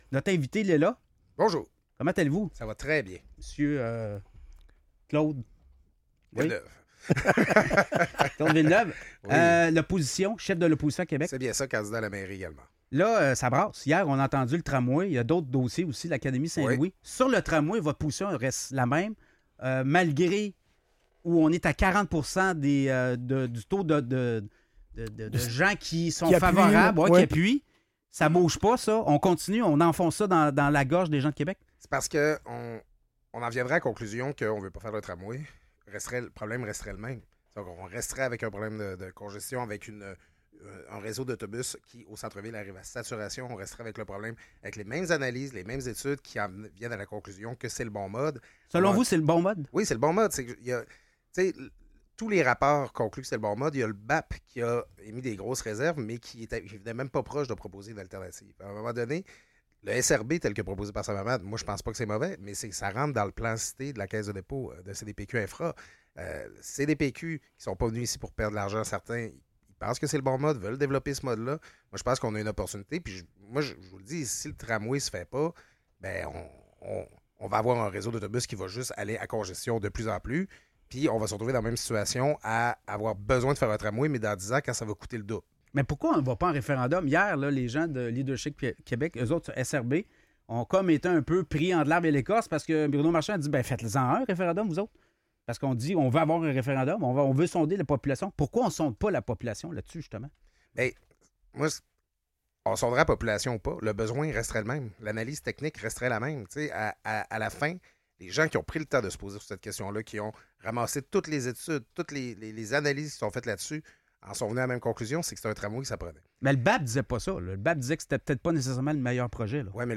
Entrevue avec Claude Villeneuve